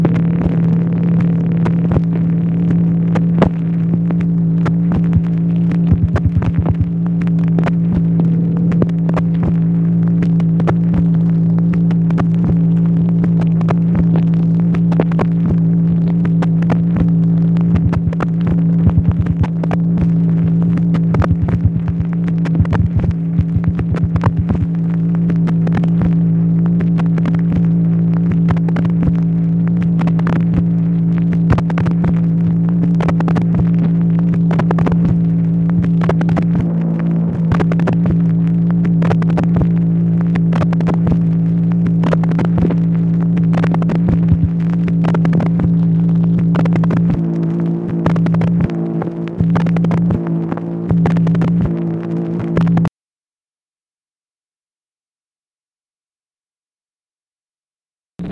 MACHINE NOISE, July 2, 1964
Secret White House Tapes | Lyndon B. Johnson Presidency